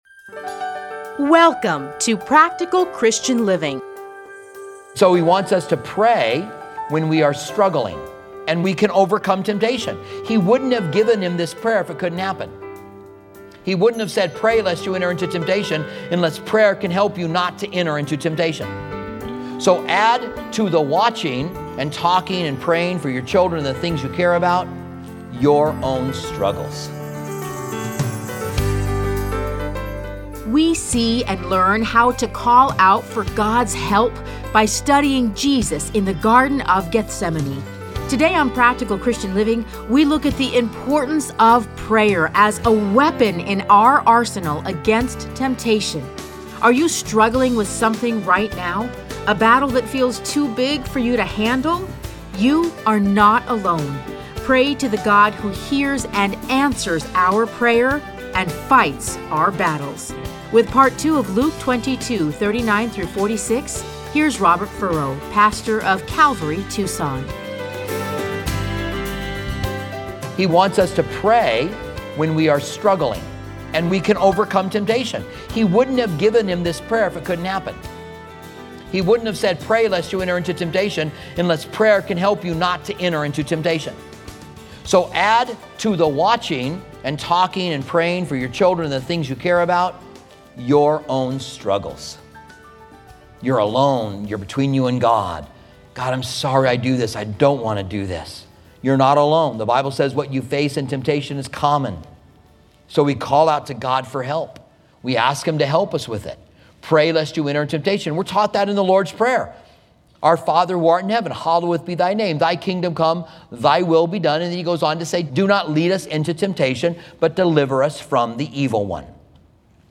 Listen to a teaching from Luke 22:39-46.